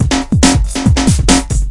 描述：用fl7和reborn rb338做的一个Indusjungle drumloops。Tr909的声音里面
Tag: tr909 断线 梧桐 丛林